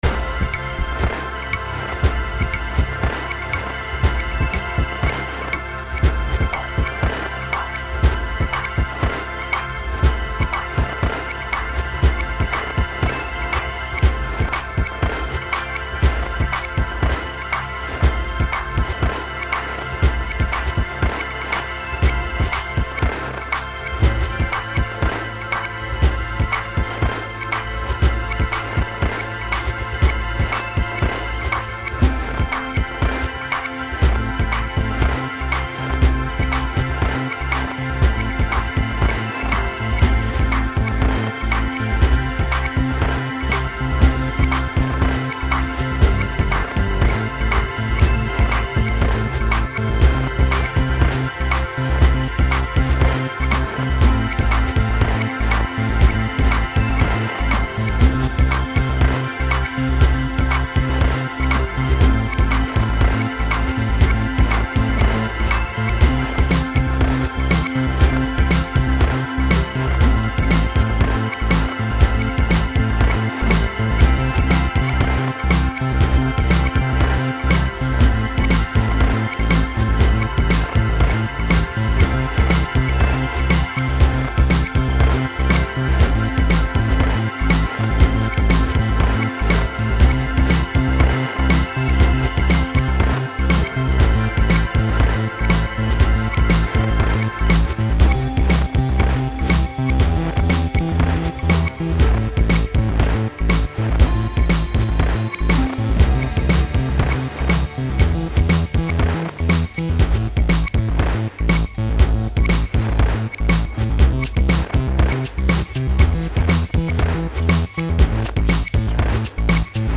I like it when the snaredrum comes.